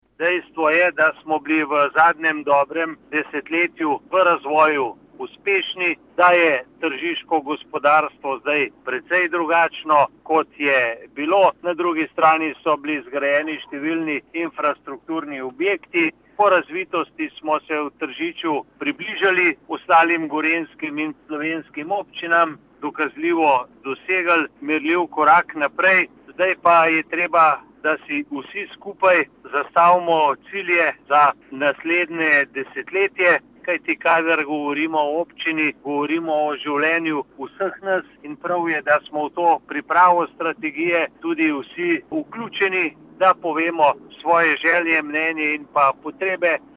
izjava_mag.borutsajoviczupanobcinetrzicorazvojuobcine.mp3 (1,1MB)